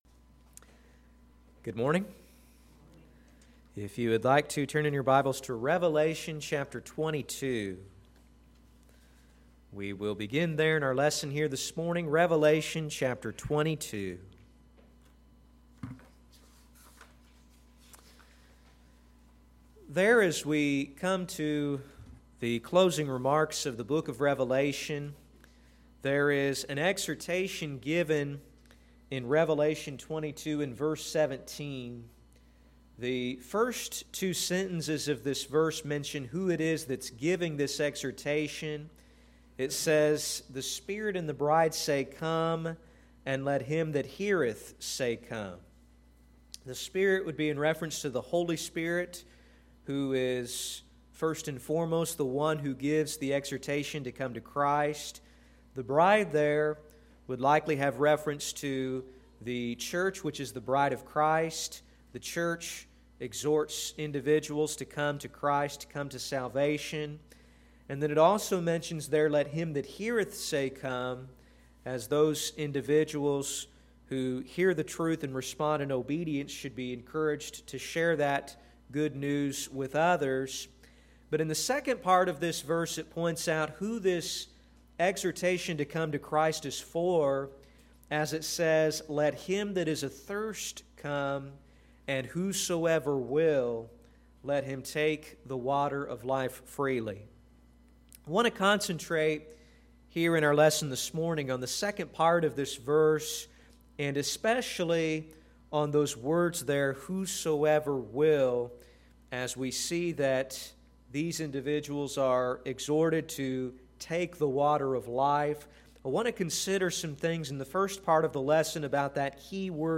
Sermons - Olney Church of Christ
Service: Sunday PM